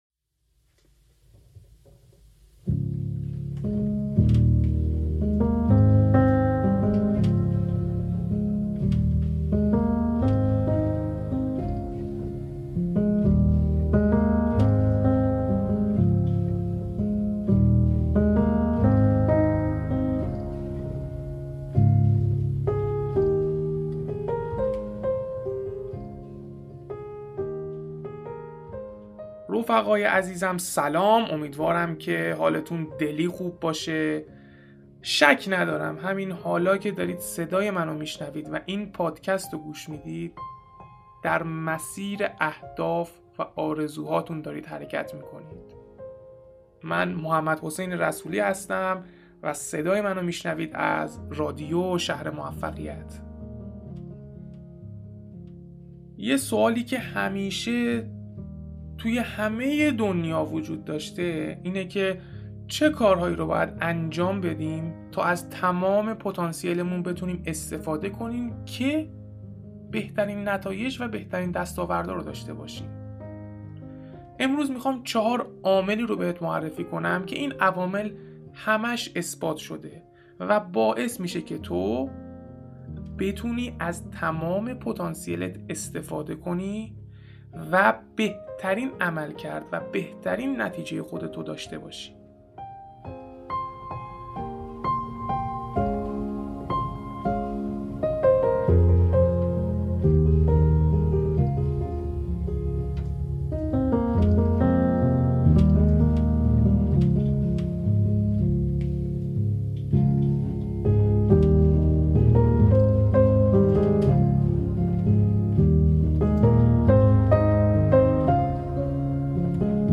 با گوش دادن به این پادکست راهکار‌هایی را برای بیشتر شدن اثربخشی کارهایتان یاد میگیرید. زبان این پادکست دوستانه و انگیزشی است و بیشتری تاثیر را روی شما خواهد گذاشت.